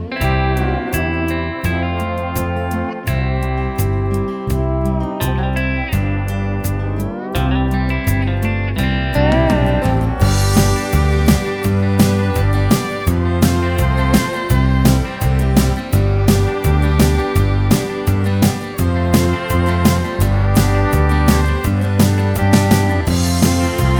no Backing Vocals Country (Male) 3:13 Buy £1.50